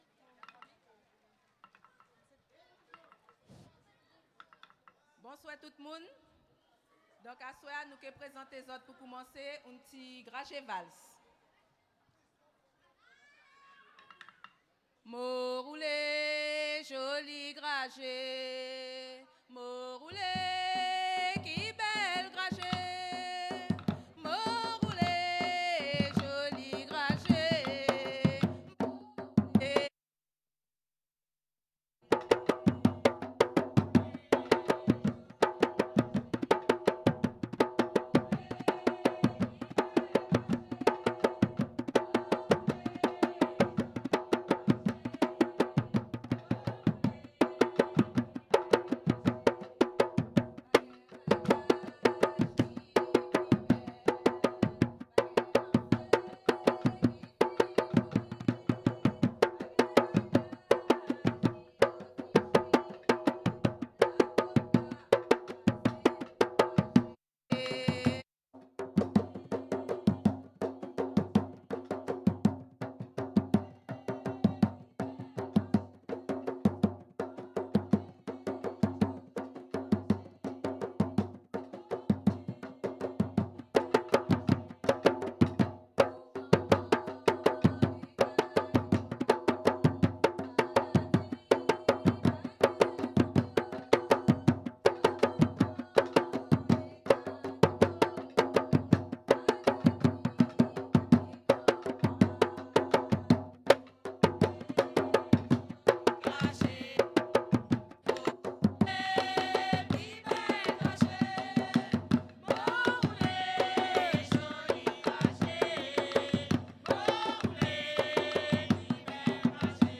La voix est inaudible sur une partie du morceau.
danse : grajévals
Pièce musicale inédite